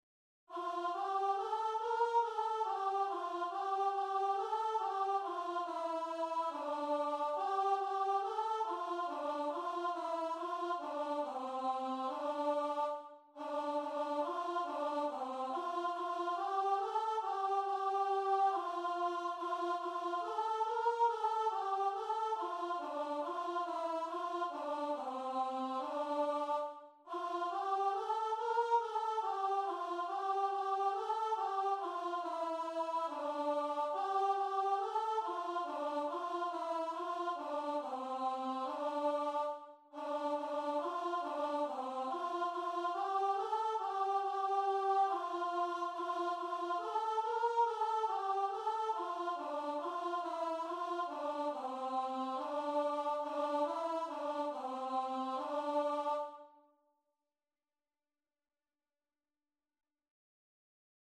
Free Sheet music for Choir
Traditional Music of unknown author.
4/4 (View more 4/4 Music)
C major (Sounding Pitch) (View more C major Music for Choir )
Christian (View more Christian Choir Music)